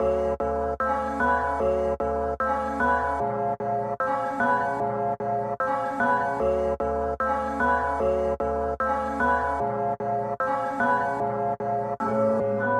钢琴劈啪声循环
Tag: 75 bpm Hip Hop Loops Piano Loops 2.15 MB wav Key : F